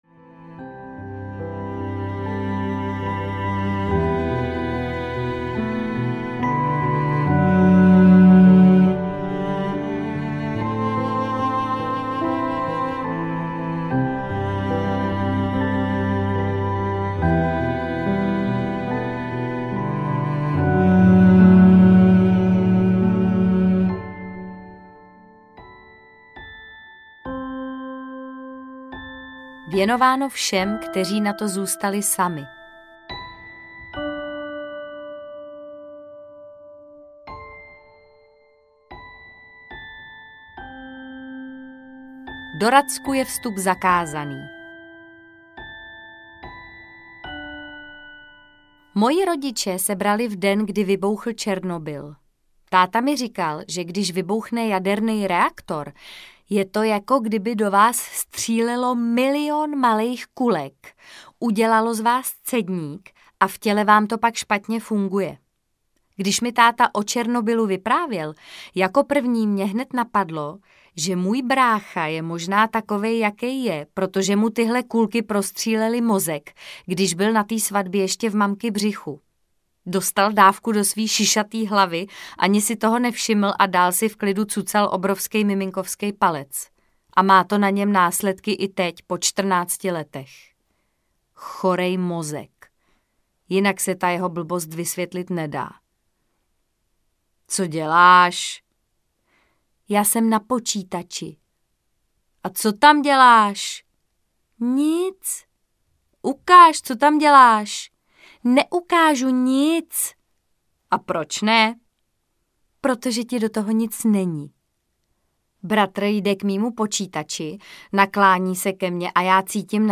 Poupátka audiokniha
Ukázka z knihy
• InterpretMartha Issová